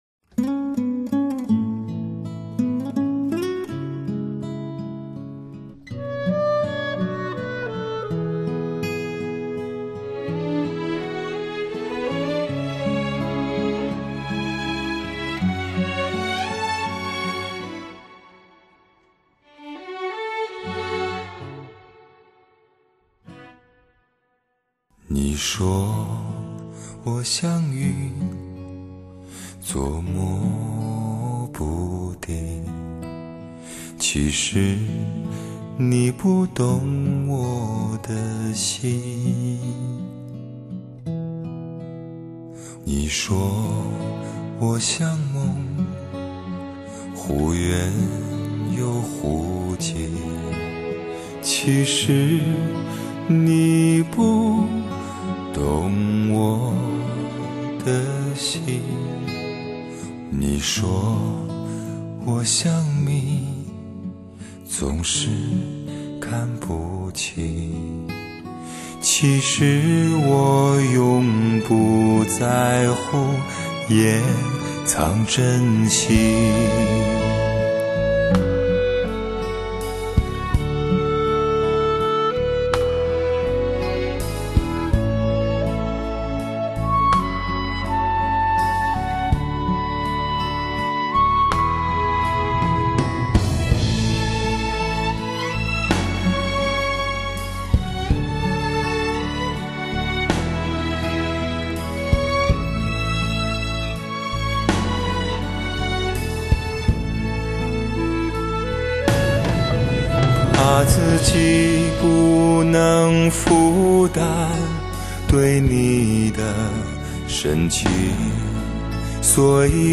台湾最男人的声音